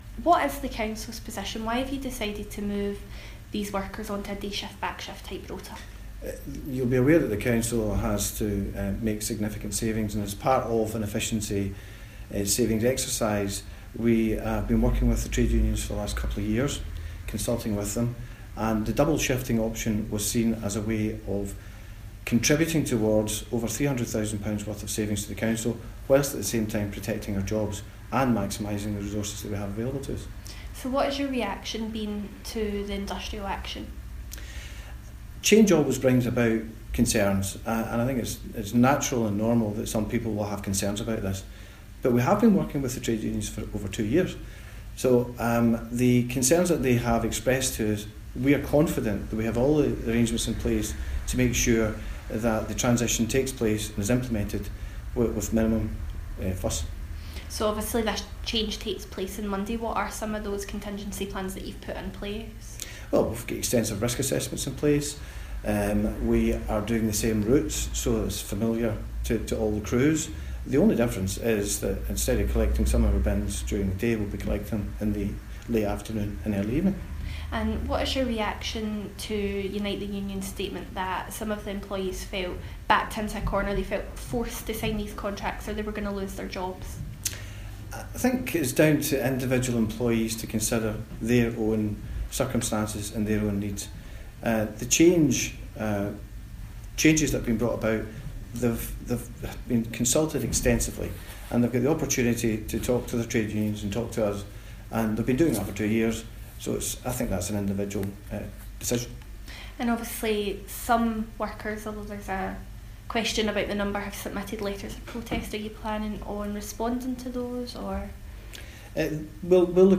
speaks to our reporter